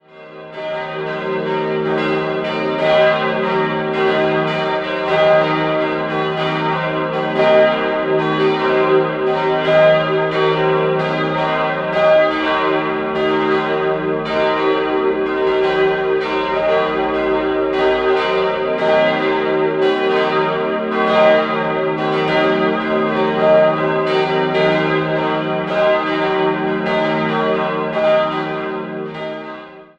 Die Georgskirche mit ihrem wuchtigen Riegelturm wurde in den 1950er-Jahren errichtet und am 22. April 1956 eingeweiht. 1987 kam es zu einer größeren Renovierungsmaßnahme, bei der auch der Innenraum künstlerisch neu gestaltet wurde. 4-stimmiges Salve-Regina-Geläut: es'-g'-b'-c'' Die Glocken wurden 1960 von der Gießerei Perner in Passau gegossen.